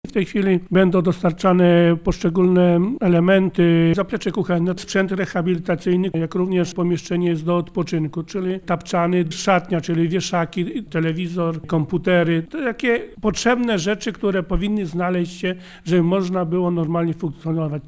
- Wszystko będzie sukcesywnie dostarczane w najbliższych dniach, tak by jeszcze w grudniu nowa placówka była gotowa na przyjęcie seniorów - zapewnia burmistrz Modliborzyc Witold Kowalik: